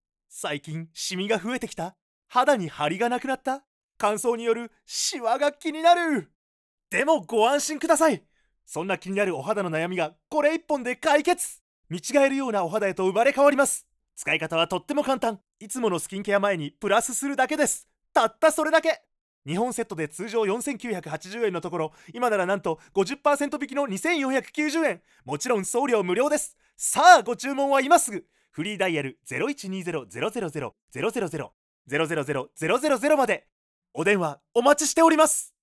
I can adapt my voice to your needs, from a calm tone to a bouncy, energetic voice.
Can speak Kansai dialect.
– Narration –
Straight
Comical